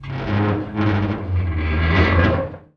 metal_scrape_deep_grind_squeak_07.wav